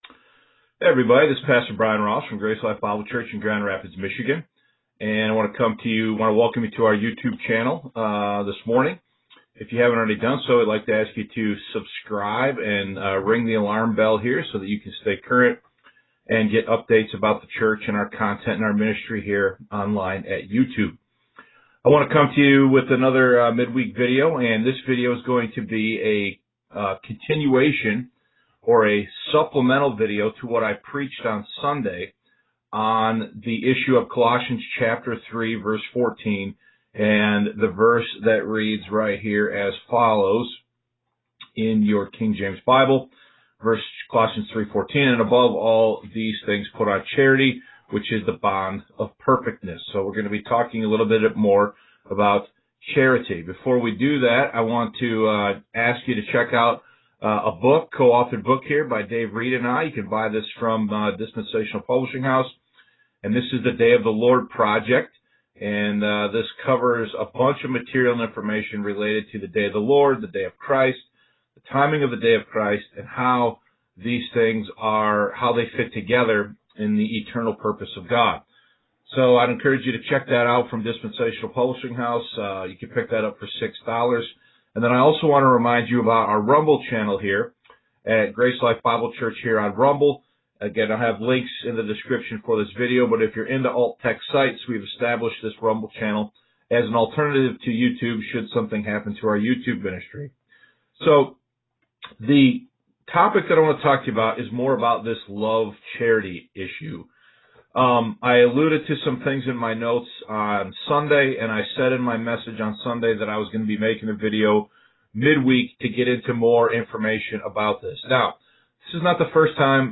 Mid-Week Messages